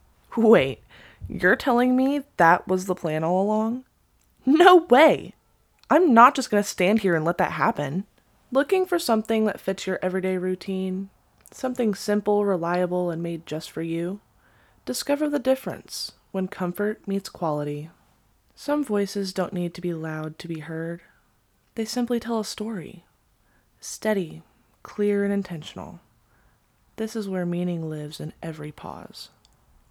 Professional Voiceover Artist
Clear, confident, and high-quality voiceovers for brands, content creators, and businesses.